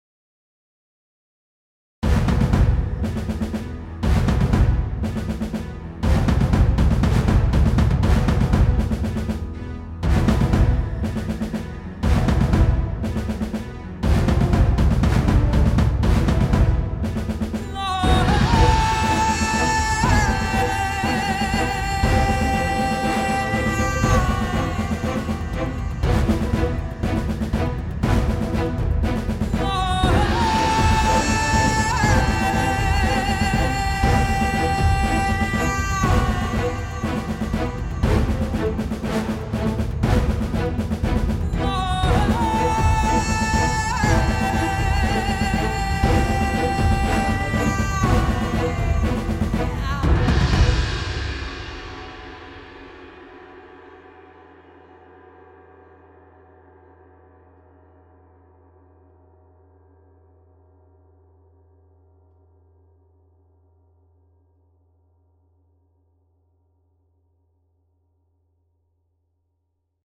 Epic Drums and Voice